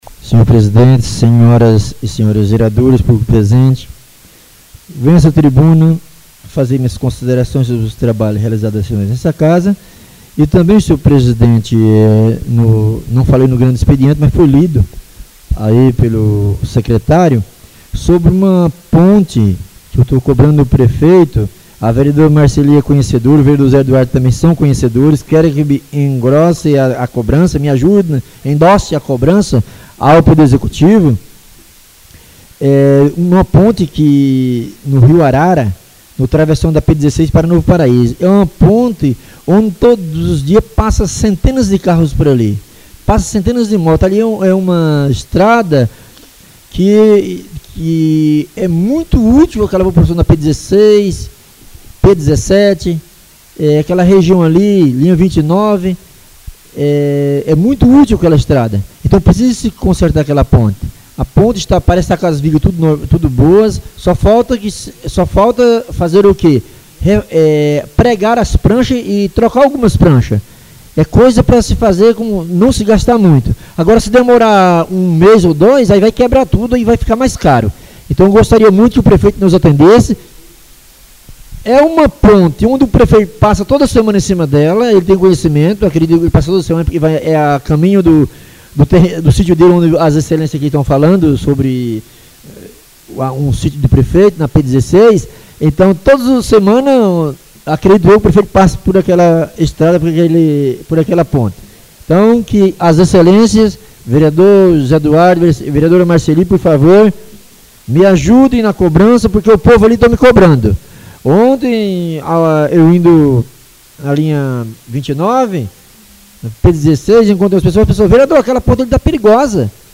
Oradores das Explicações Pessoais (26ª Ordinária da 3ª Sessão Legislativa da 6ª Legislatura)